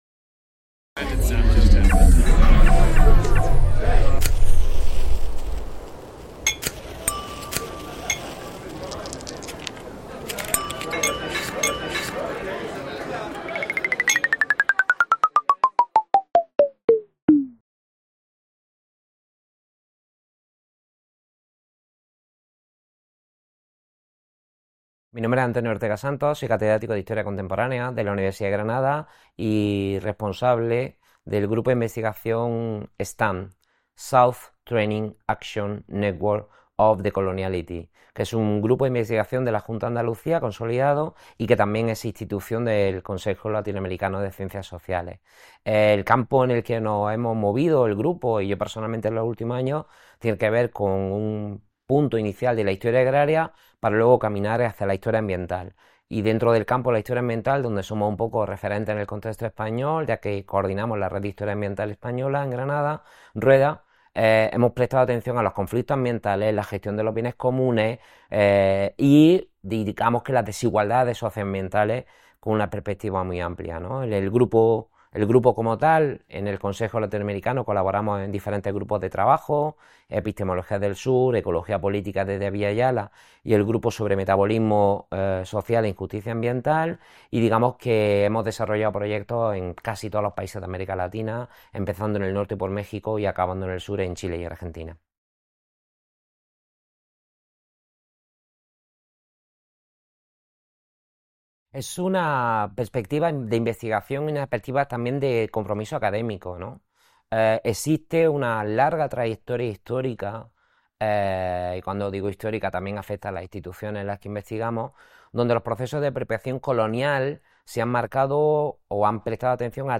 Bienes comunes, conflictos socioambientales e historia confluyen en esta entrevista